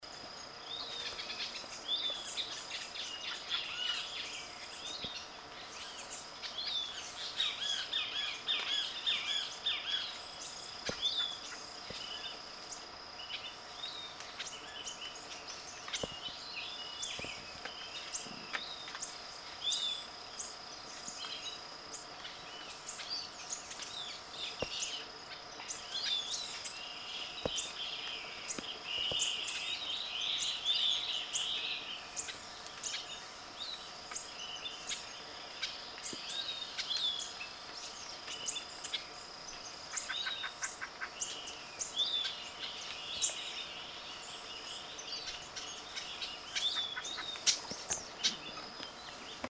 Passerini’s Tanager (Ramphocelus passerinii) Xeno-canto org
231-passerinis-tanager-ramphocelus-passerinii-xeno-canto.mp3